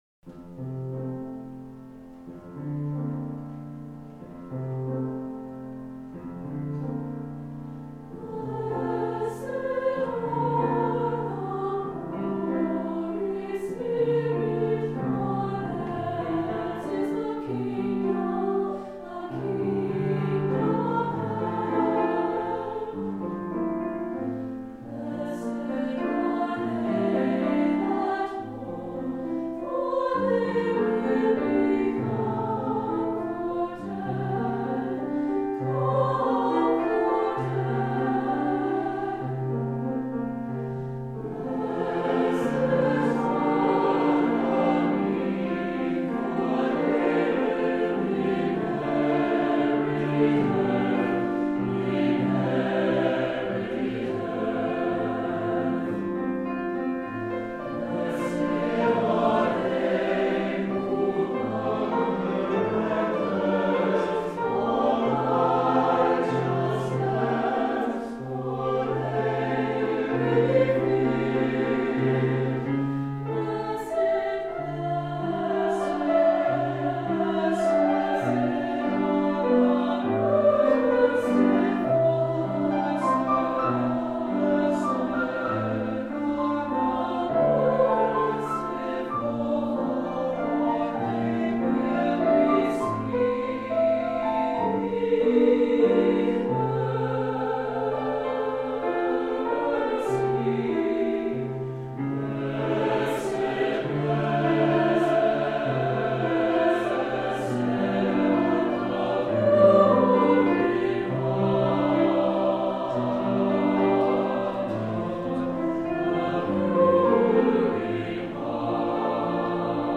Voicing: SATB